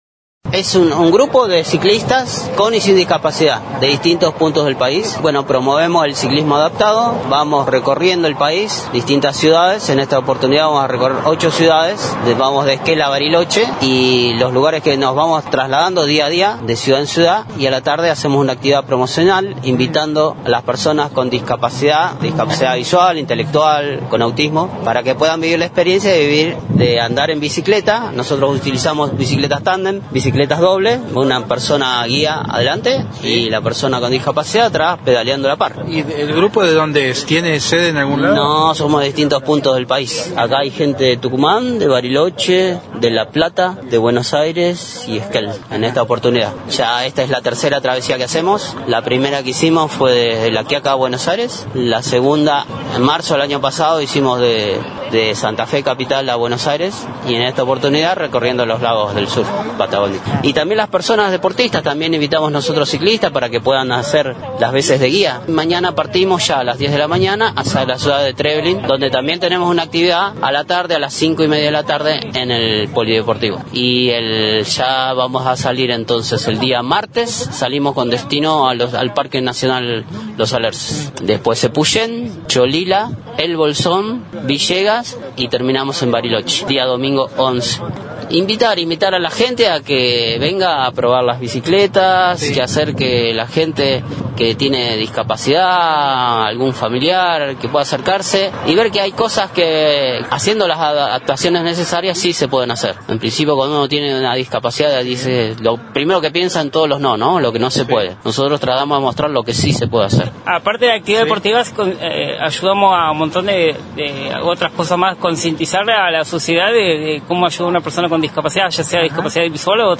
Noticias de Esquel conversó con integrantes de los grupos Los Búhos Bariloche y Atre-verse, sobre esta interesante experiencia que pasó por Esquel.